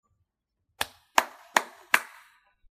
Hnads Clapping
描述：hands clapping loud
标签： Hands stairwell Clapping
声道立体声